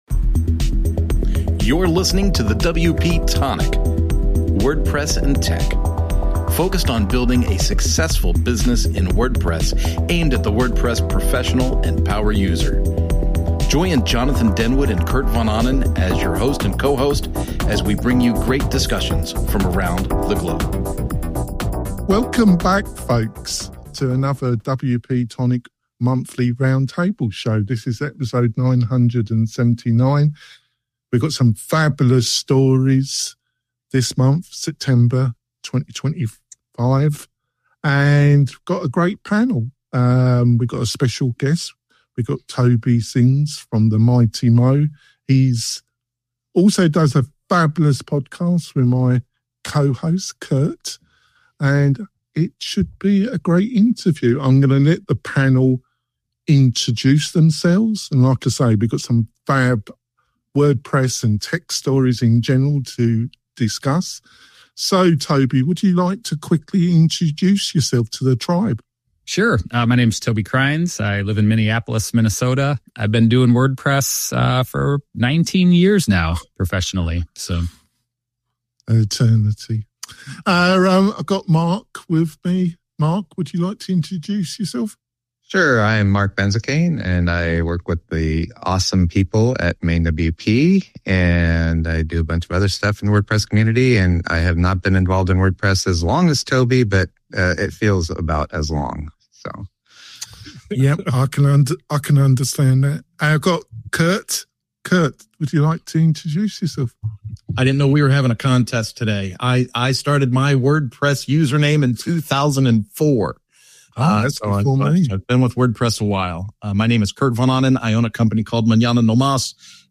1 #979- WP-Tonic This Month in WordPress & Tech Round Table Show For September, 2025 1:07:37